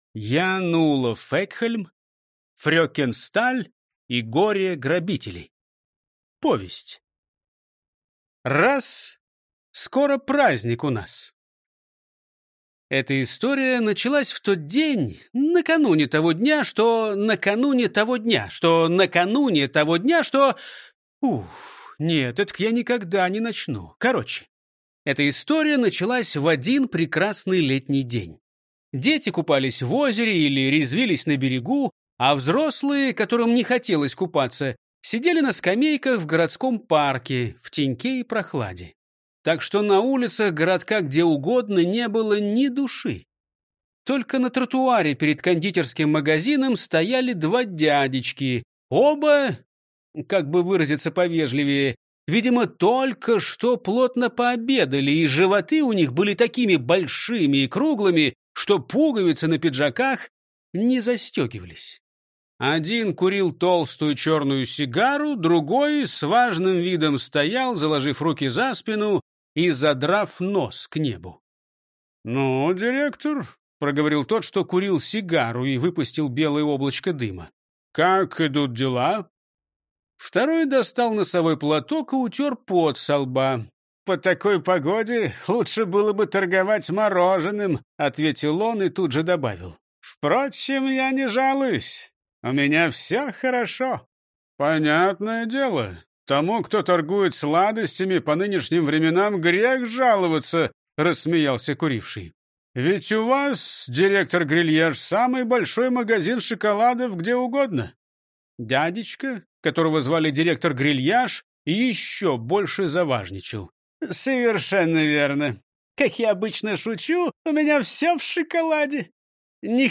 Аудиокнига Фрёкен Сталь и горе-грабители | Библиотека аудиокниг